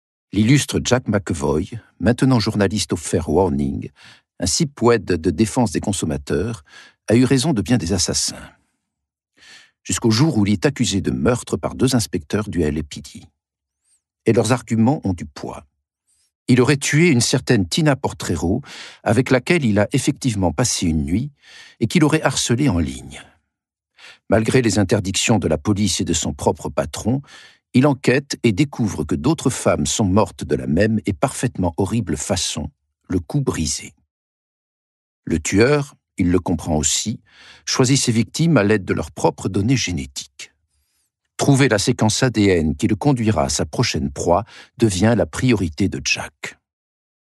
compact disques audio